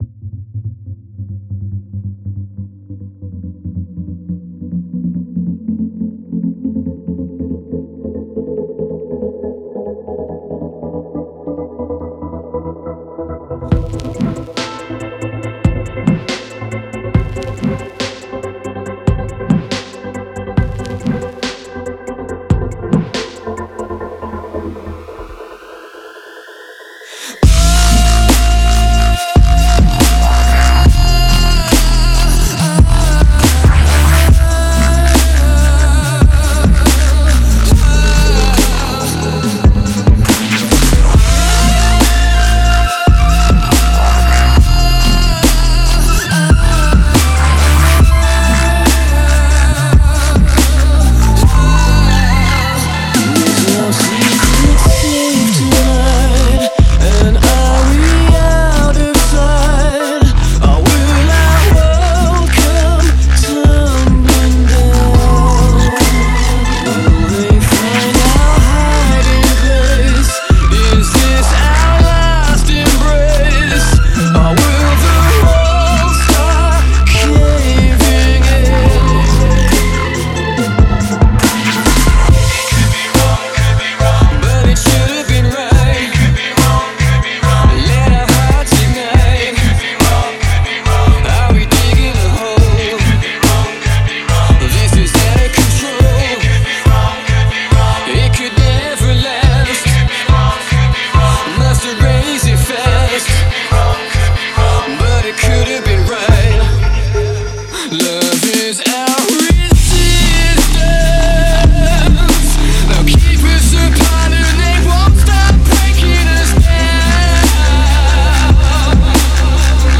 Стиль музыки: Dub Step